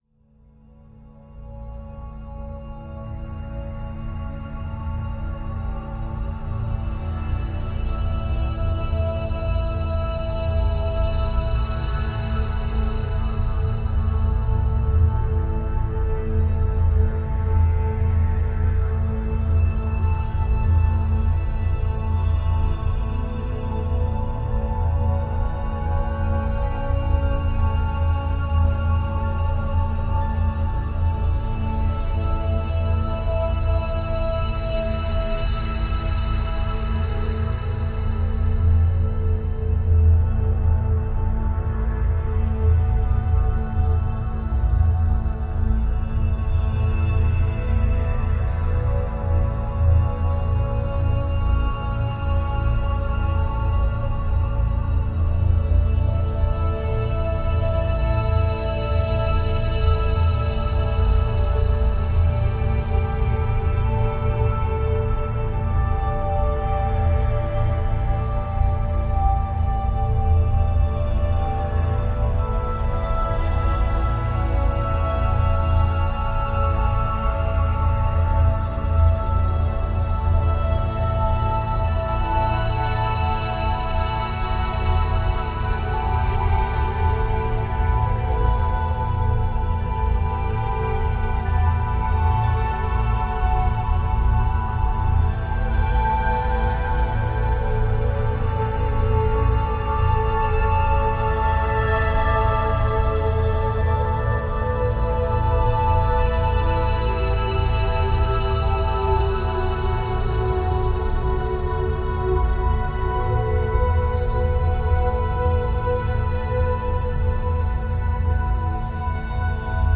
Ce chant vibratoire facilite l’équilibre énergétique (masculine et féminine)
CHANTS VIBRATOIRES